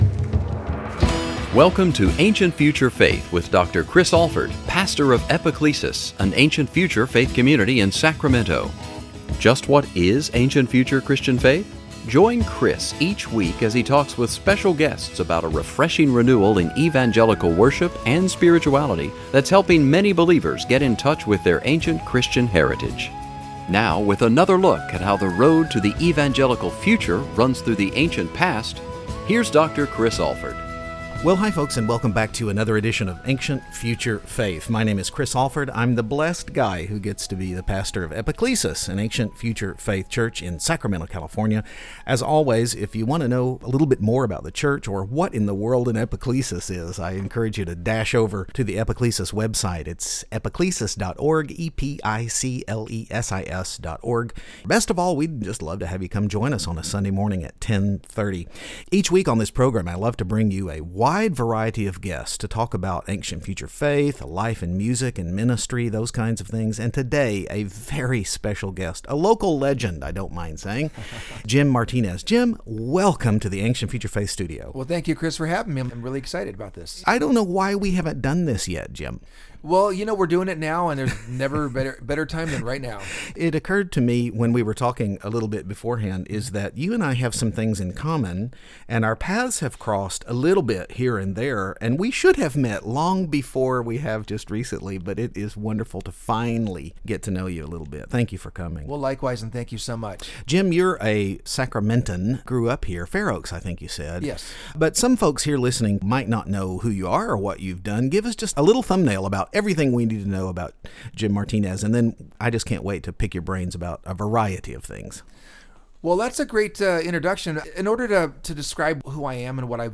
Interestingly enough, we also touched on several ancient-future theological truths. Join us for this wonderful conversation!